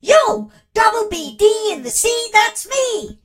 darryl_lead_vo_06.ogg